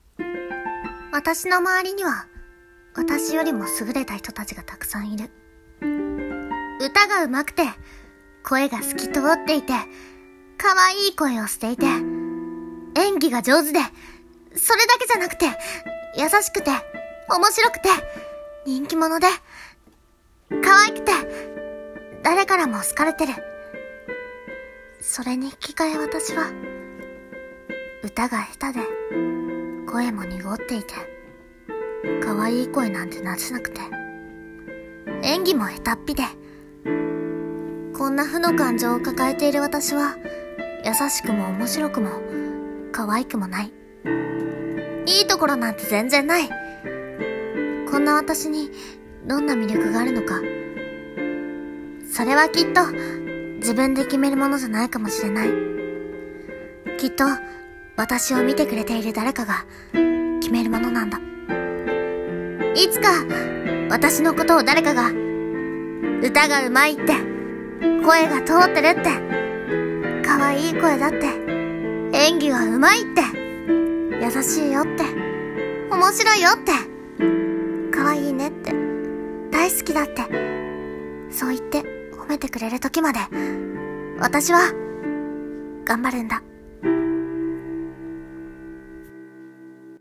【声劇】自信消失